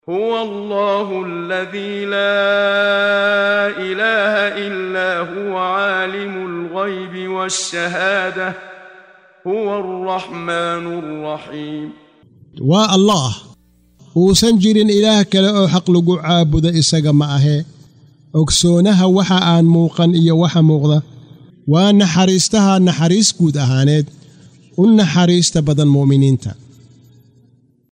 Waa Akhrin Codeed Af Soomaali ah ee Macaanida Suuradda Al-Xashar ( Kulminta ) oo u kala Qaybsan Aayado ahaan ayna la Socoto Akhrinta Qaariga Sheekh Muxammad Siddiiq Al-Manshaawi.